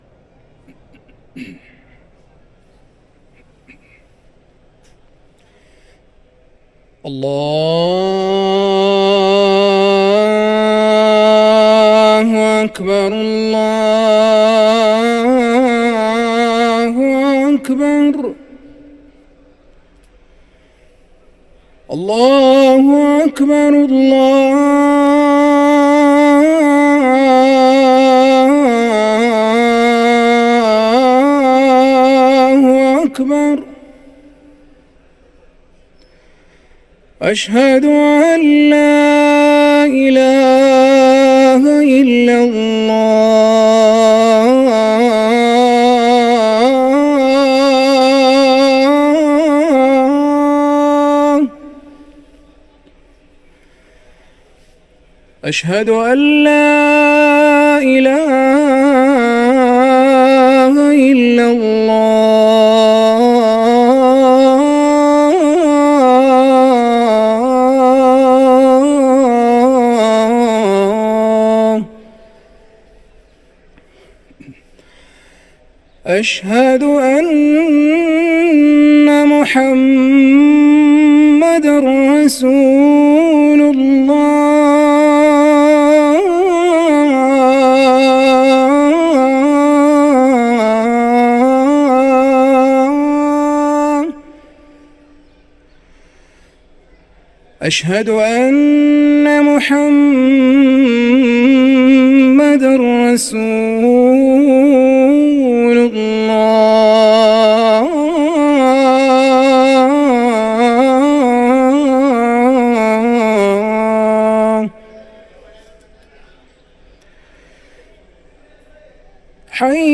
أذان الجمعة الأول